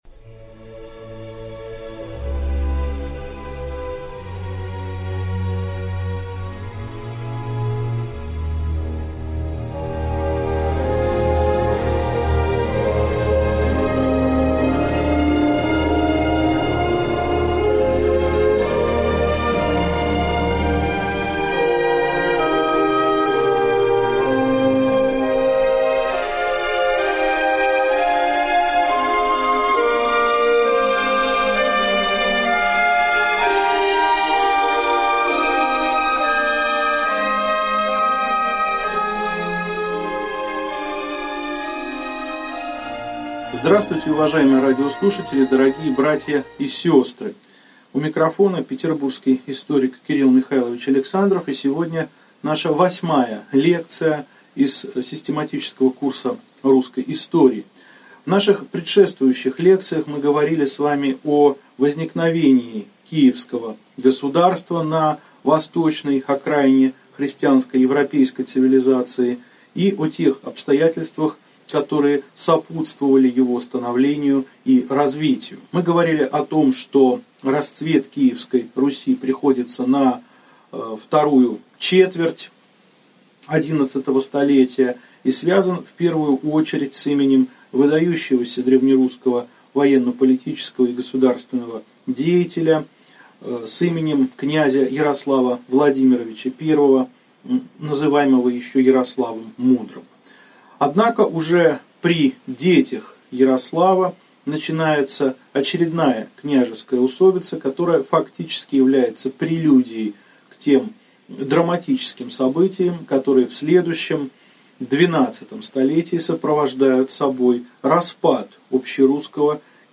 Аудиокнига Лекция 8. Правление внуков Ярослава. Усобицы | Библиотека аудиокниг